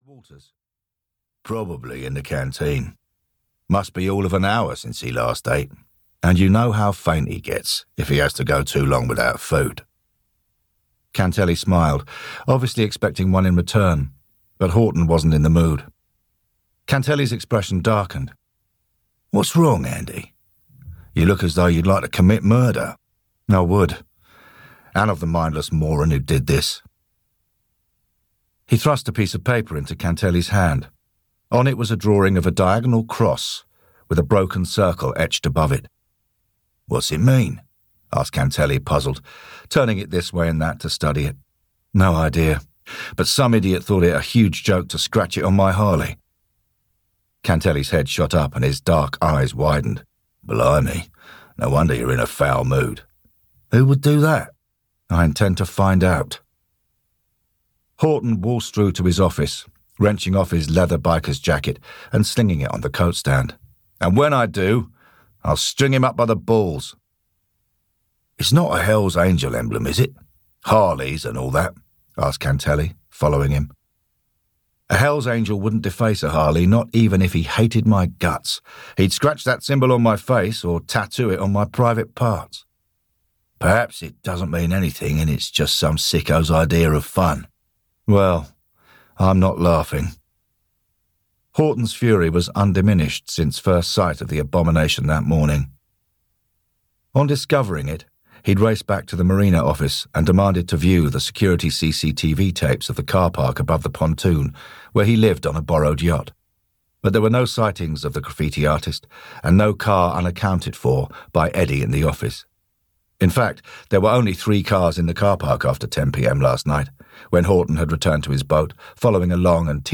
Audio knihaThe Portchester Castle Murders (EN)
Ukázka z knihy